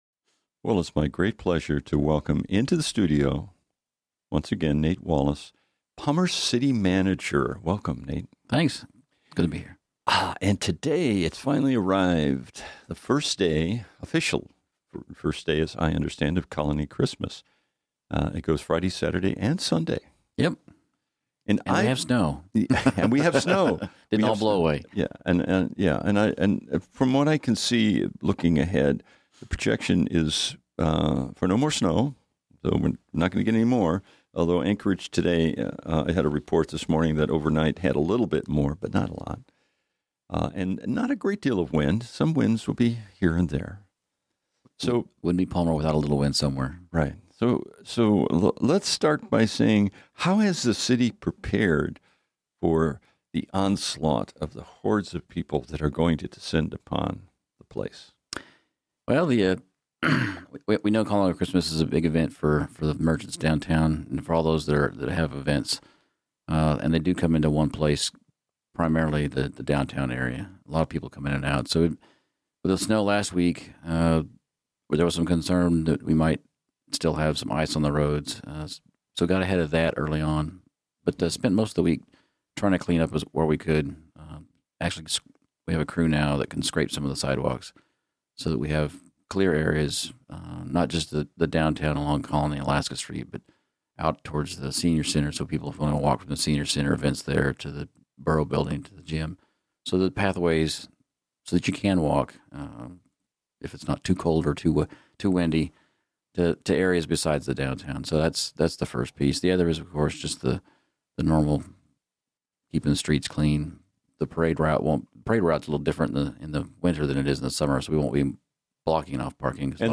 Discussions and interviews with employees and administrators from the city of Palmer